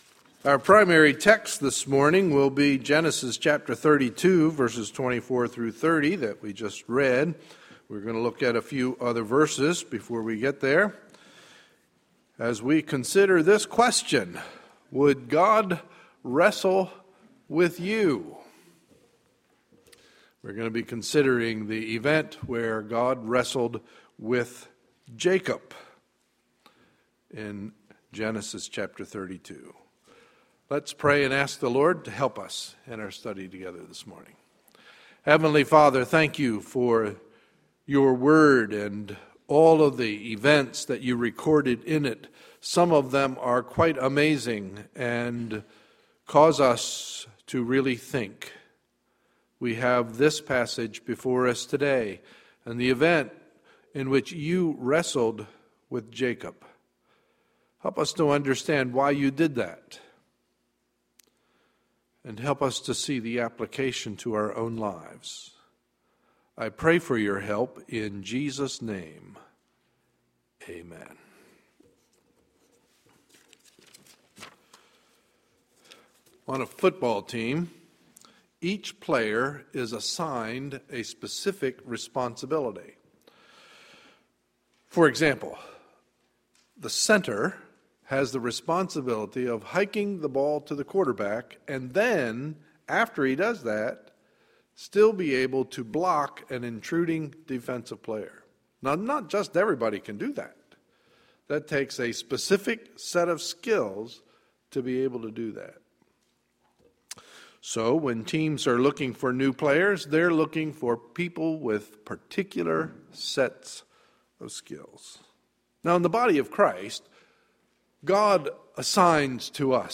Sunday, February 24, 2013 – Morning Message